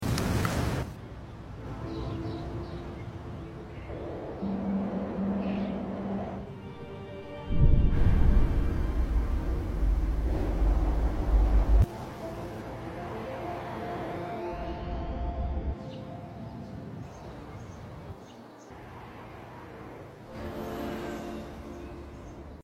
Captured in the streets of Manchester celebrating the new PB store opening at Trafford Centre.